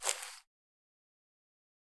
footsteps-single-outdoors-001-04.ogg